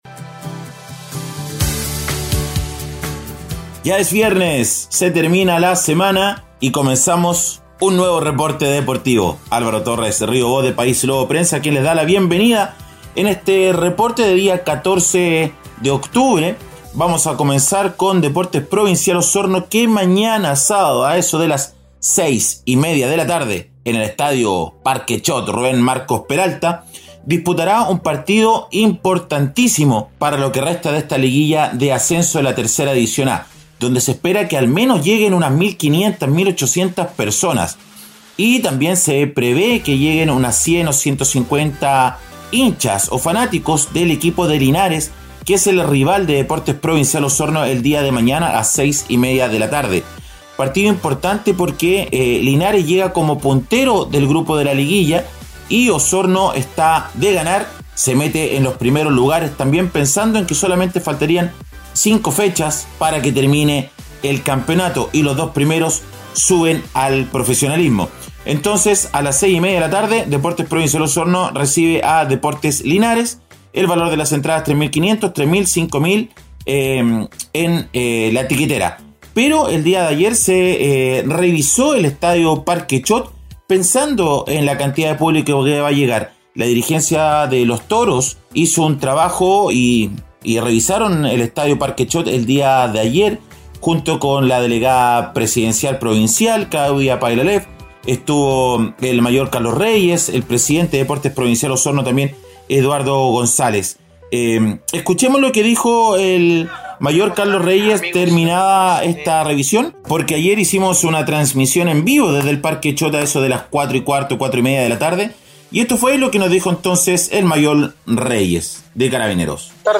breve reporte